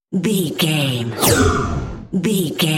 Airy whoosh pass by
Sound Effects
bouncy
bright
futuristic
pass by
sci fi